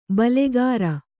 ♪ balegāra